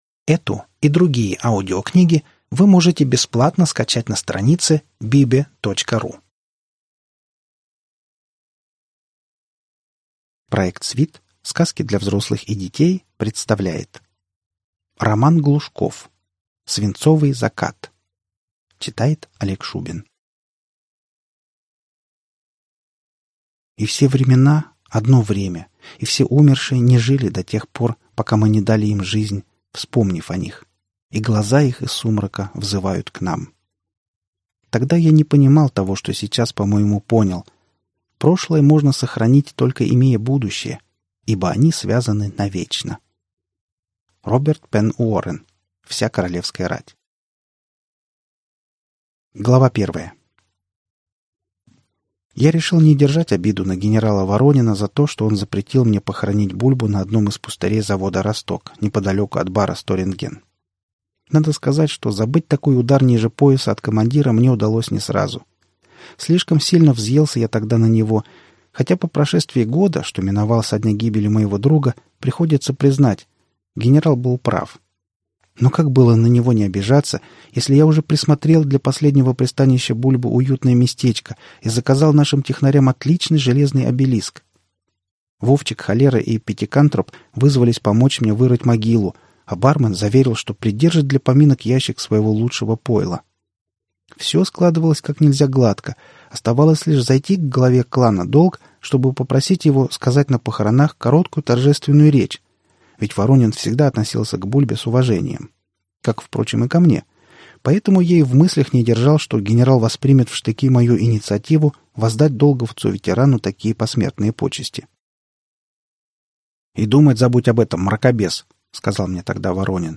Аудиокнига Свинцовый закат | Библиотека аудиокниг